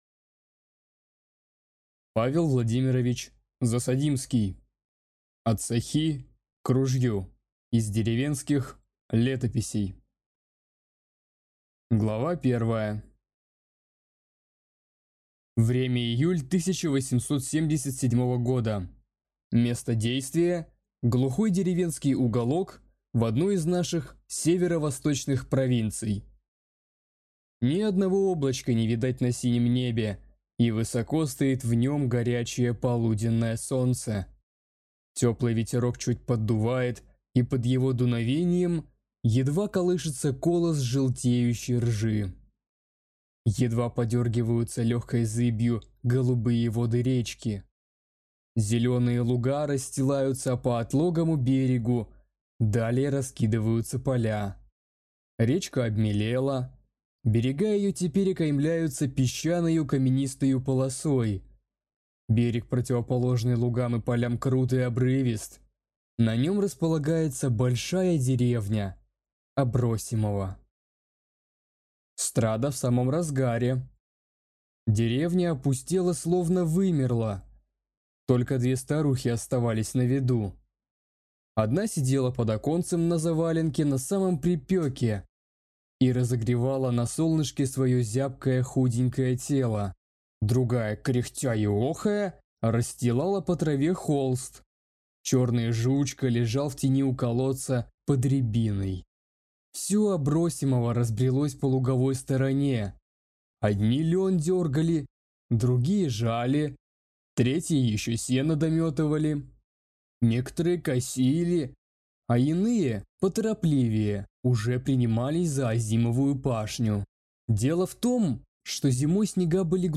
Аудиокнига От сохи к ружью | Библиотека аудиокниг